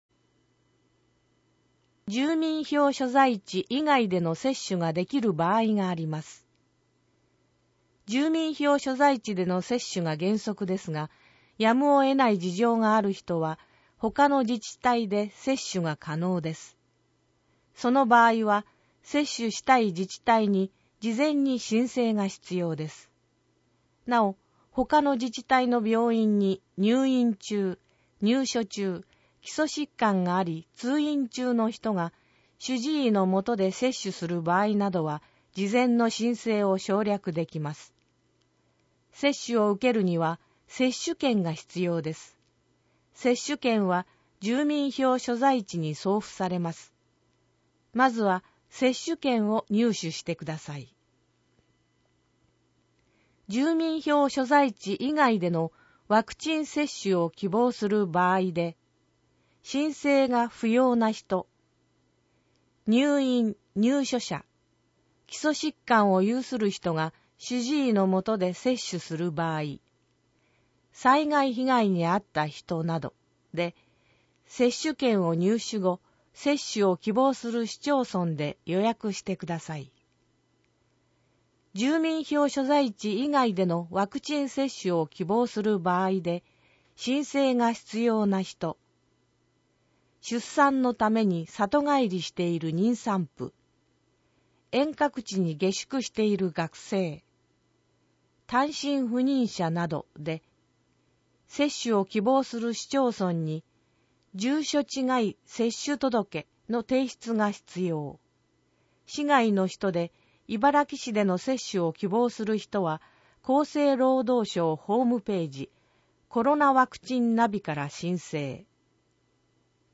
毎月1日発行の広報いばらきの内容を音声で収録した「声の広報いばらき」を聞くことができます。